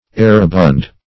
Errabund \Er"ra*bund\, a.